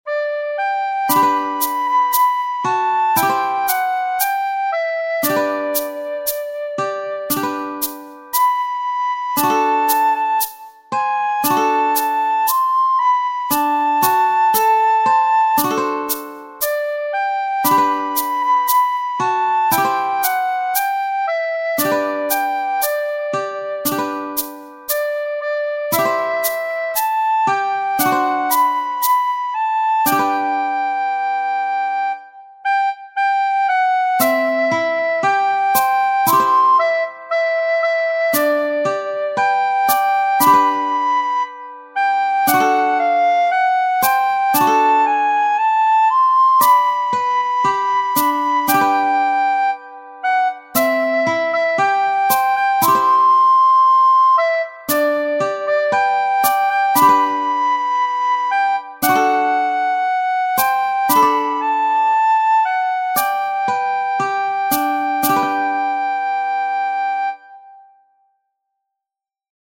Aloha Oe is a farewell Hawaiian folk song written around 1878 by Lydia Liliʻu Loloku Walania Kamakaʻeha (1838-1917) know as Queen Liliʻuokalani.
The lyrics not only reflect the goodbye moment but also nature accompanying the feeling of sadness through rain and cliffs. This version uses the recorder and two traditional Hawaiian instruments, the ukelele and the ipu-heke.